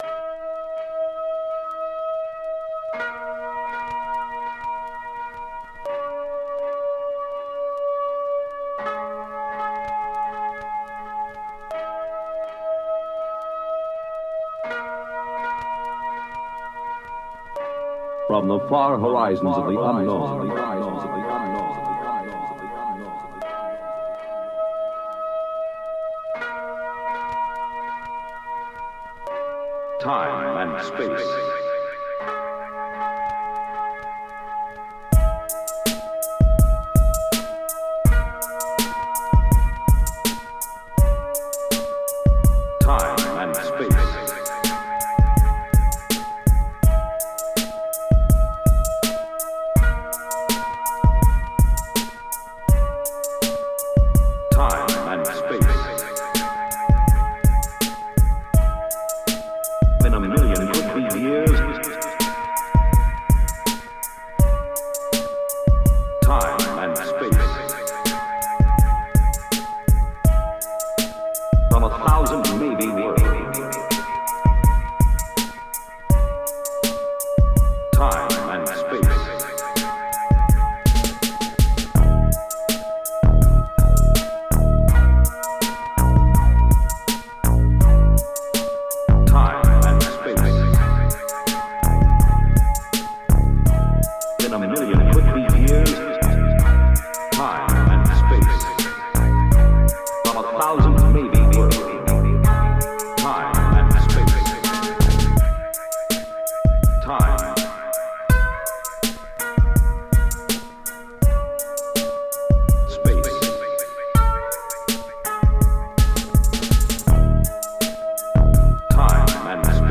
mix of Japanese koto and sci-fi radio drama